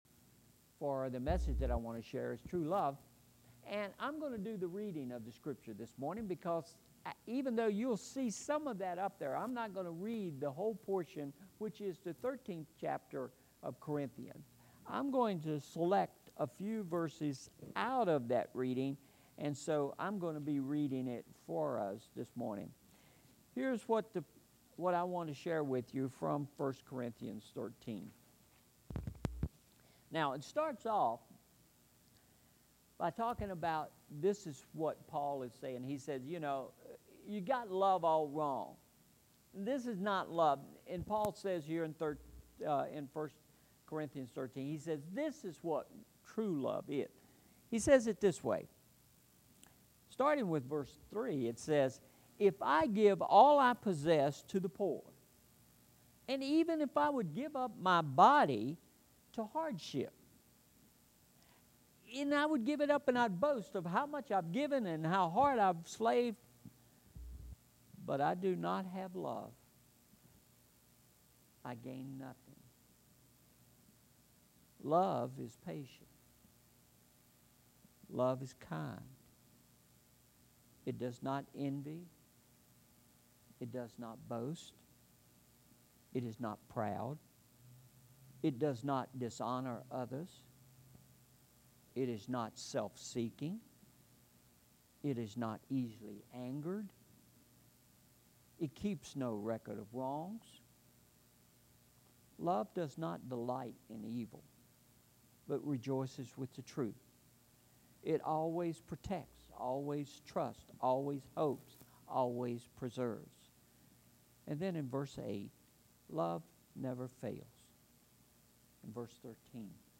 Sermon Series: Love Gifts: What Matters Most – Part 1